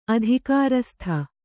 ♪ adhikārastha